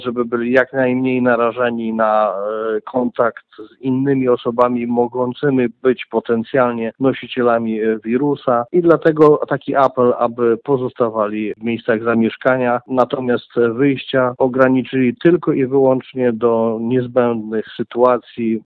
Takie komunikaty z radiowozów straży miejskiej usłyszycie na ulicach Ełku.